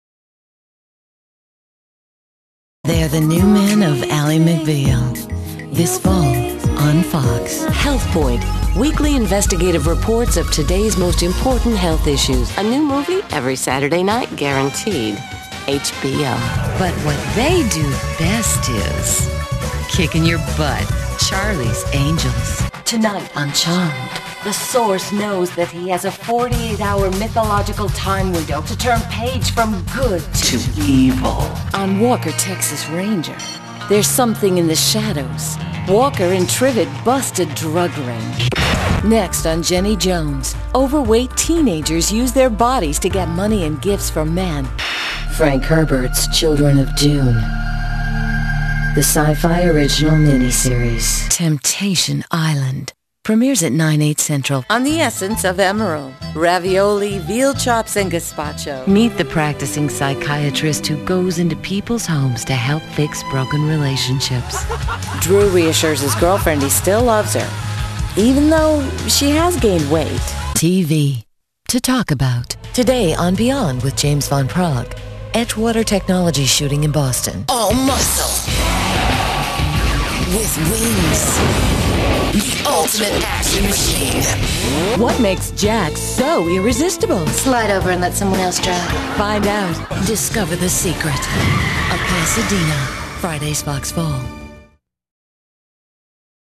Voiceover
Promo (2:00)